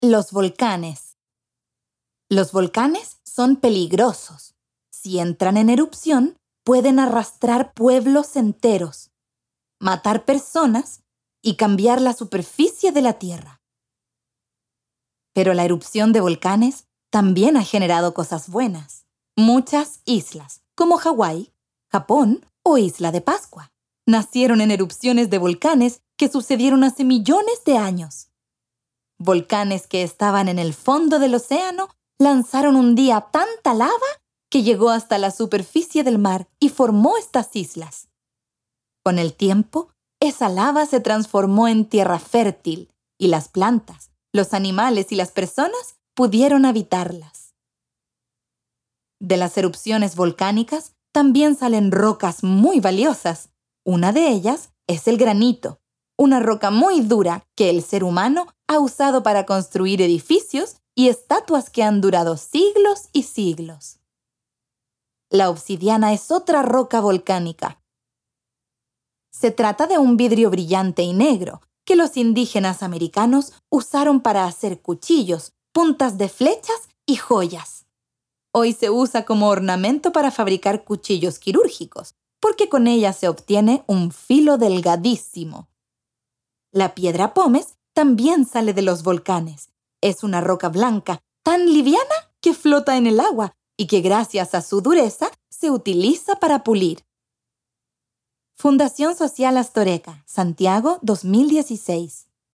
Audiolibro - Extracto Tomo 2